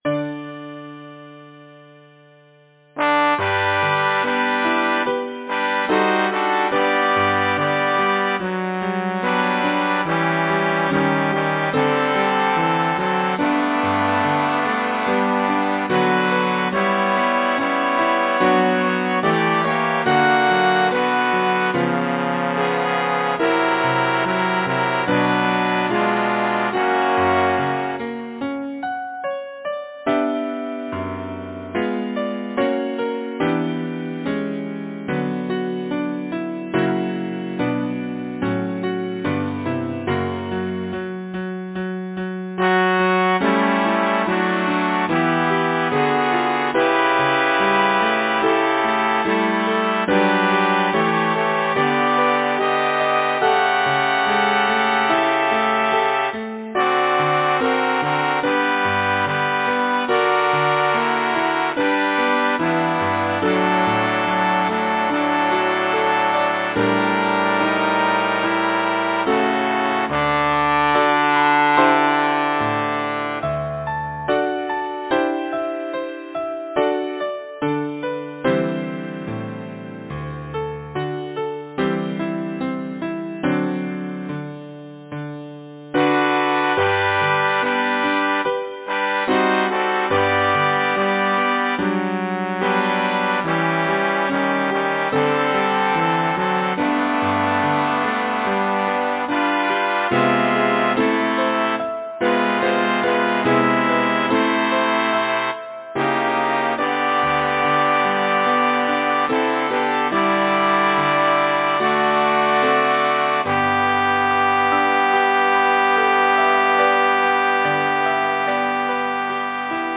Number of voices: 4vv Voicing: SATB Genre: Secular, Partsong
Language: English Instruments: Piano